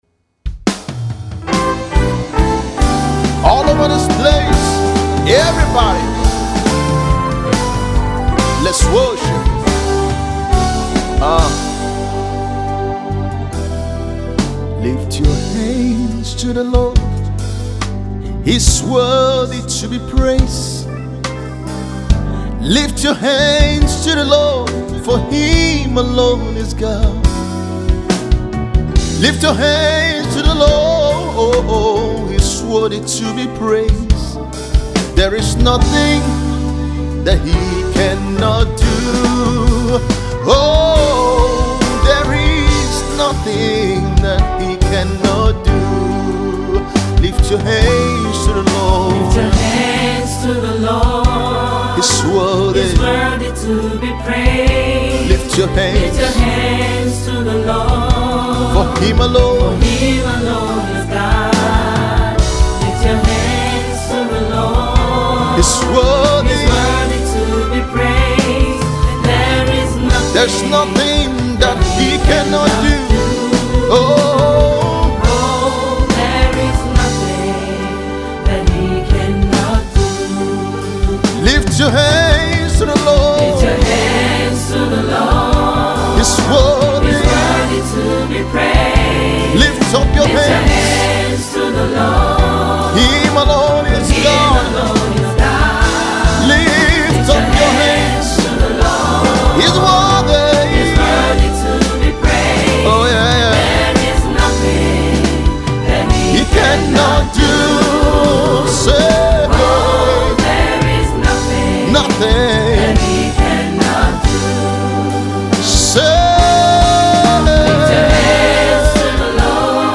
Gospel recording artiste